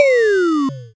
fall-a.ogg